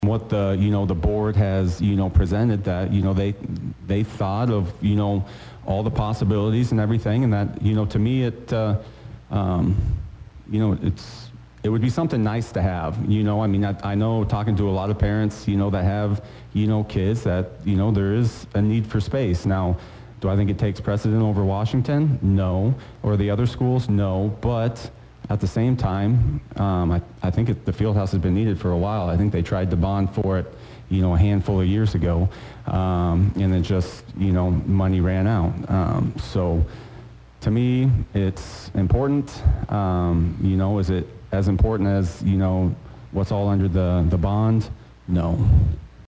The bond issues highlighted Sunday’s Meredith Communications Meet Your Candidate’s Forum.